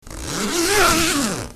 Звуки молнии, одежды
На этой странице собраны звуки молний на одежде — от резких до плавных, с разными типами тканей.
Звук расстегивания молнии на одежде